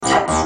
Sons et loops gratuits de TB303 Roland Bassline
Basse tb303 - 78